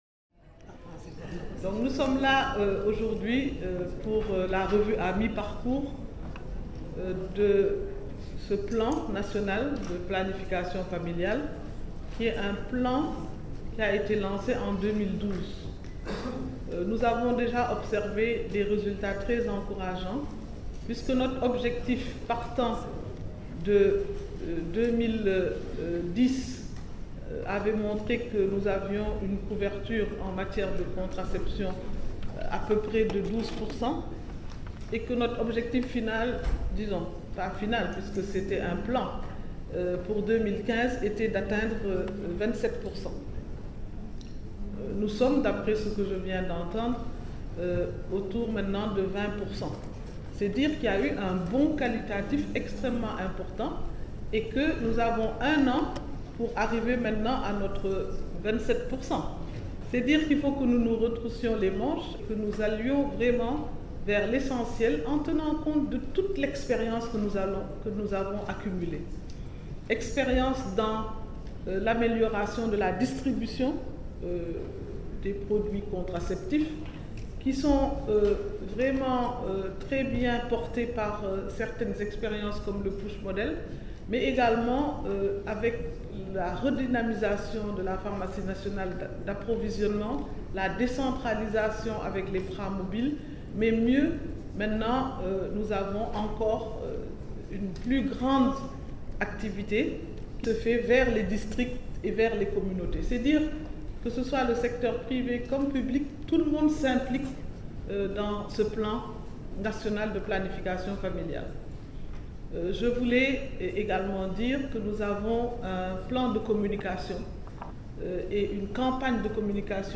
Point_de_presse_de_Awa_Marie_Coll_Seck_sur_la_revue_nation ale_du_PF_français.mp3 (3.53 Mo)